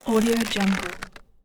دانلود افکت صدای کشش پوست
• تنوع: ما طیف گسترده‌ای از افکت‌های صدای کشش پوست را برای موقعیت‌های مختلف مانند کشش، پارگی، و جدا شدن ارائه می‌دهیم.
با دانلود افکت صدای کشش پوست از انواتودانلود، می‌توانید به راحتی به صدای واقعی و دلهره‌آوری که برای پروژه‌تان نیاز دارید دست پیدا کنید.
Sample rate 16-Bit Stereo, 44.1 kHz